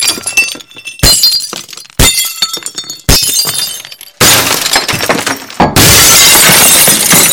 razbitoe-steklo_25514.mp3